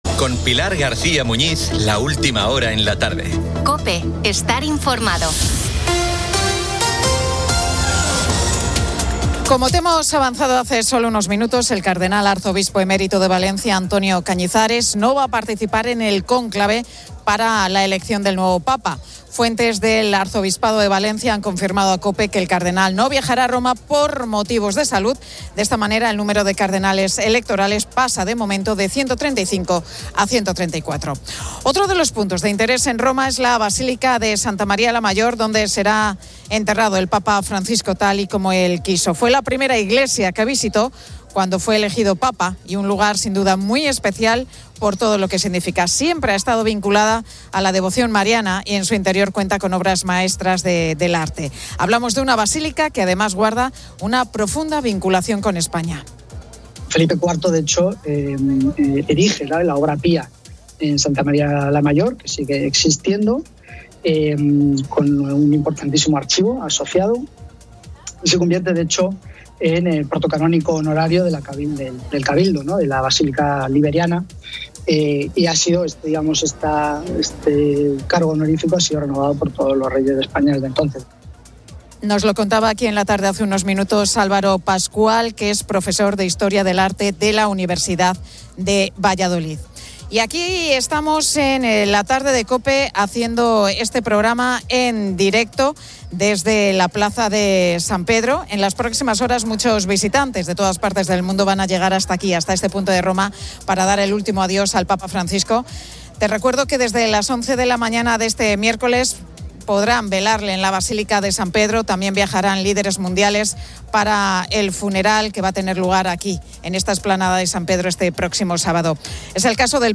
La Tarde 18:00H | 22 ABR 2025 | La Tarde Pilar García Muñiz y el equipo de La Tarde de COPE desde Roma por la muerte del Papa Francisco.